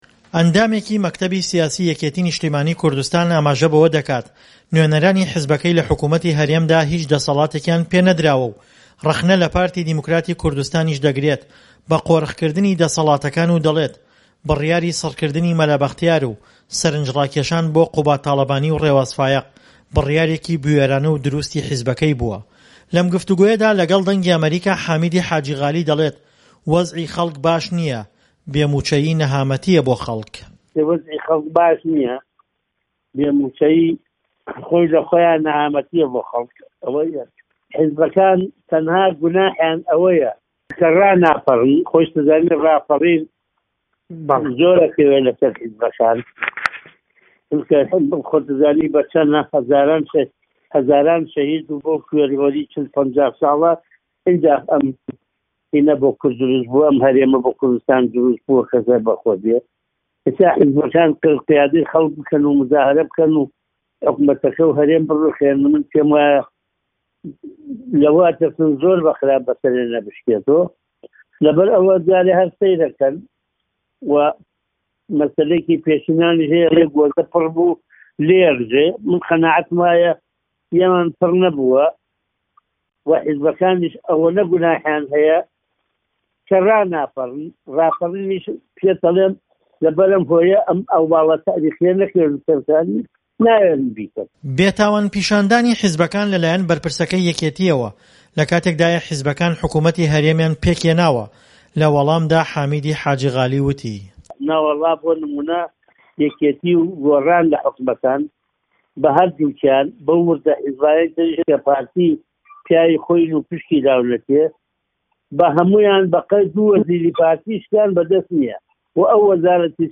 لەم گفتووگۆیەدا لەگەڵ دەنگی ئەمەریکا، حامیدی حاجی غالی، دەڵێت"وەزعی خەڵک باش نیە، بێ موچەیی نەهامەتیە بۆ خەڵک، حیزبەکان تەنها تاوانیان ئەوەیە کە ڕاناپەڕن.